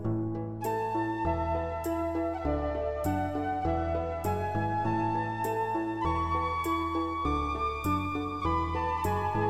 Что за инструмент звучит, флейта или? уж очень нравится его сказочное звучание, подскажите как такого звучания добиться, и где взять такой инструмент!?...